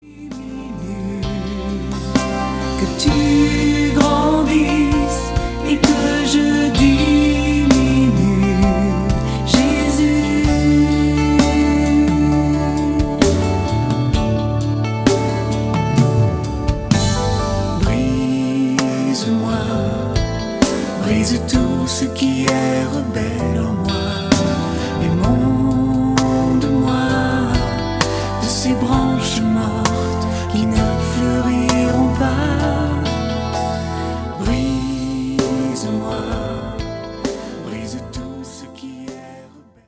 Louange (429)